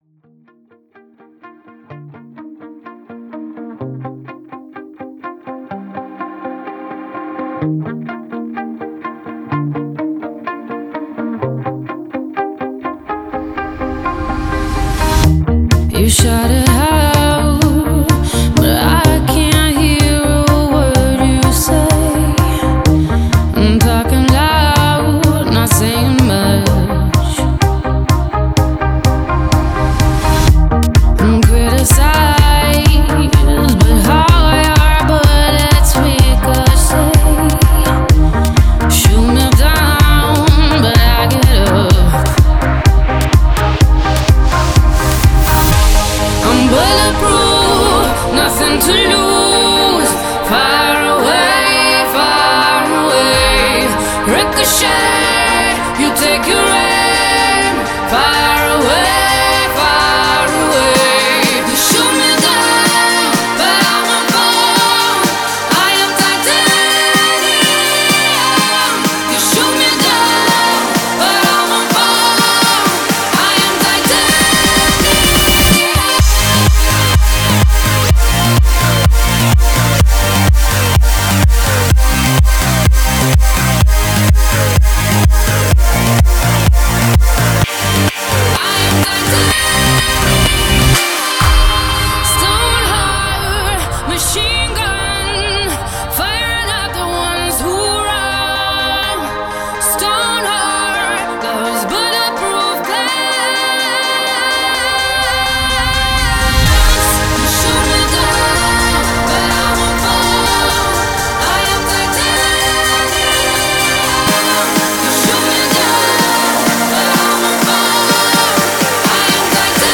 BPM126
Audio QualityLine Out